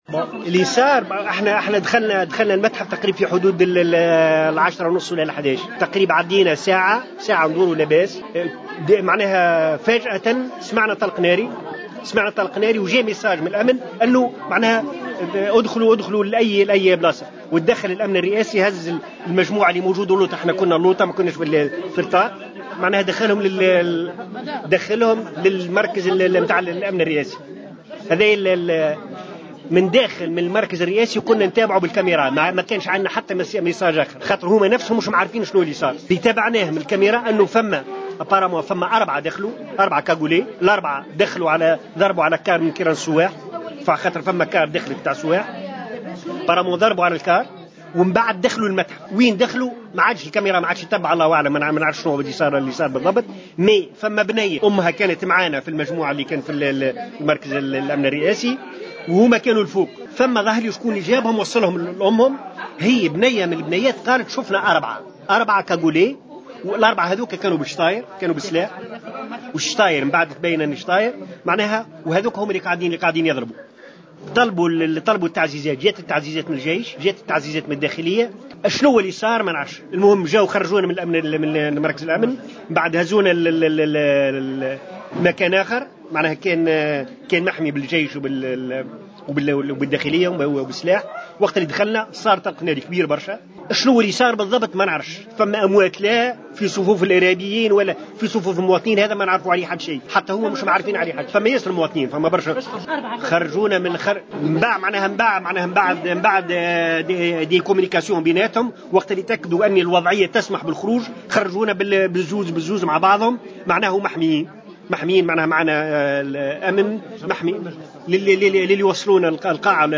شاهد عيان يروي تفاصيل الهجوم على متحف باردو